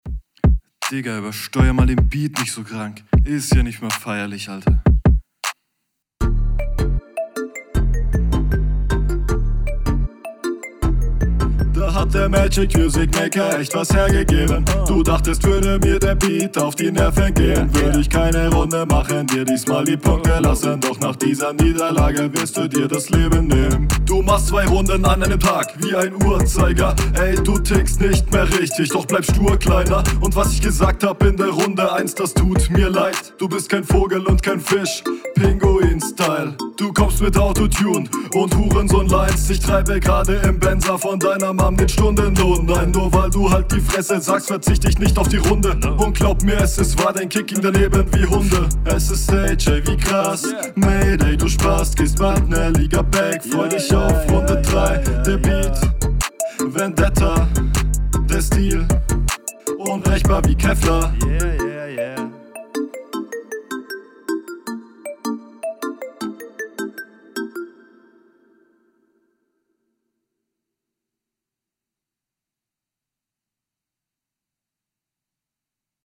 Rap ist dope, Flowst schön auf dem Beat, Autotune klingt aber Komisch …